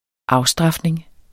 Udtale [ -ˌsdʁɑfneŋ ]